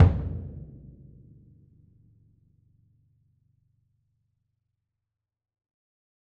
BDrumNewhit_v7_rr1_Sum.wav